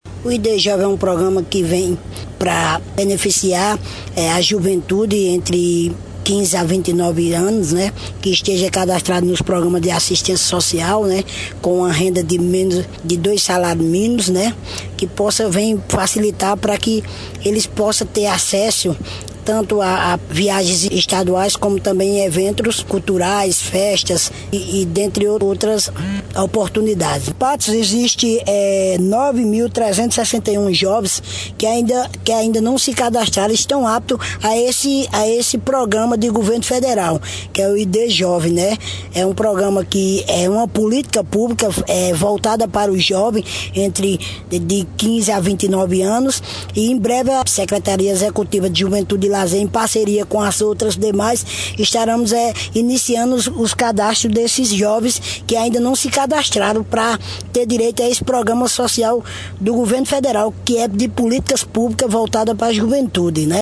Fala do secretário de Juventude e Lazer – Junho Ferreira (Chinha) –